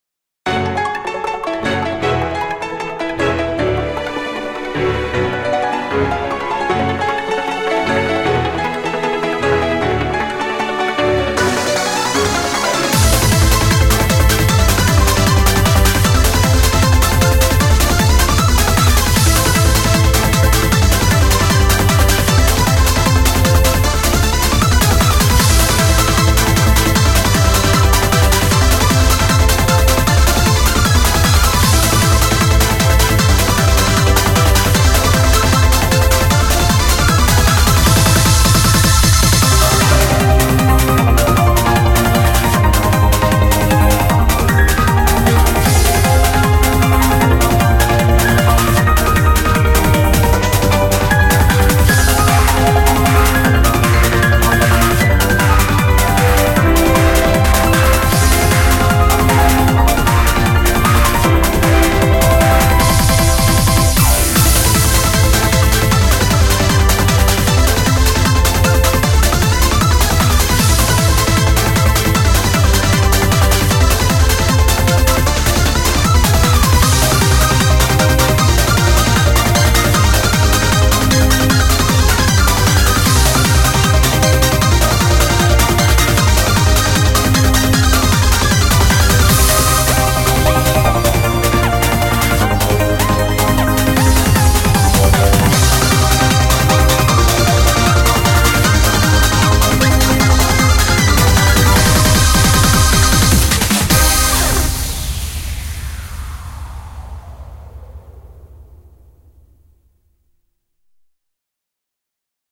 BPM154
Audio QualityPerfect (High Quality)